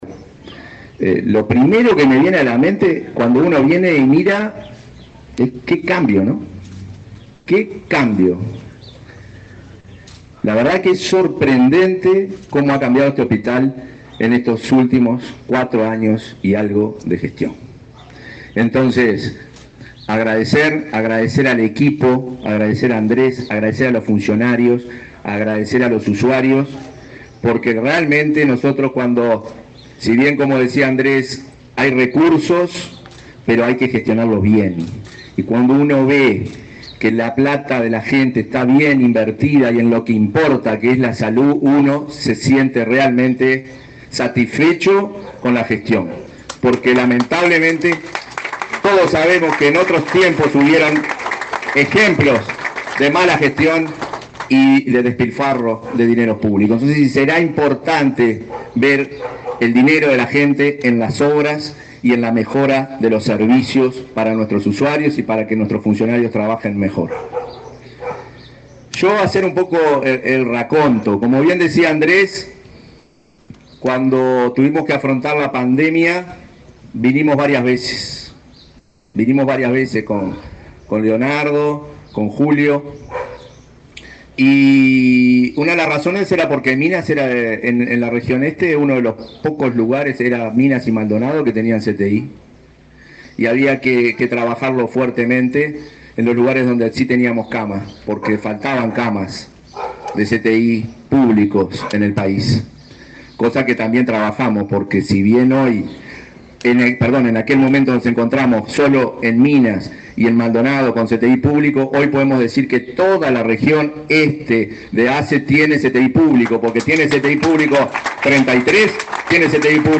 Palabras del presidente de ASSE, Marcelo Sosa
Palabras del presidente de ASSE, Marcelo Sosa 19/11/2024 Compartir Facebook X Copiar enlace WhatsApp LinkedIn La Administración de Servicios de Salud del Estado (ASSE) inauguró obras de remodelación en el área administrativa y en la farmacia del hospital departamental de Lavalleja. El presidente de ASSE, Marcelo Sosa, se expresó durante el acto.